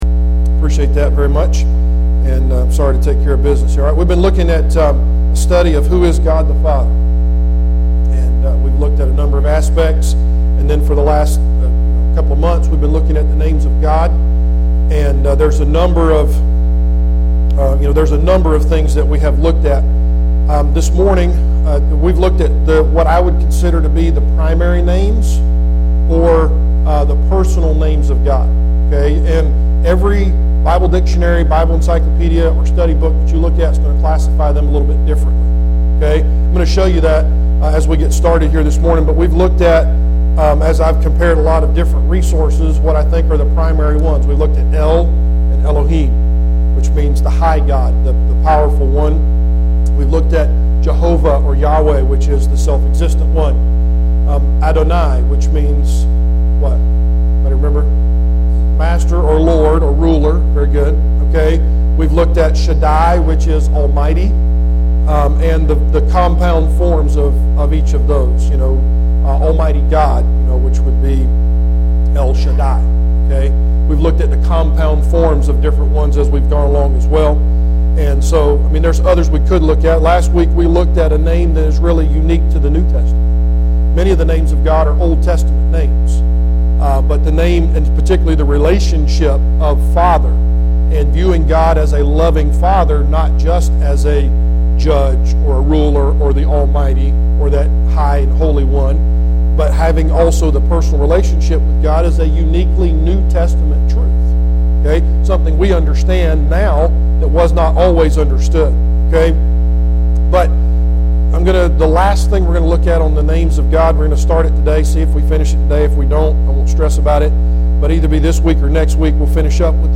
The Names of God Service Type: Adult Sunday School Class Preacher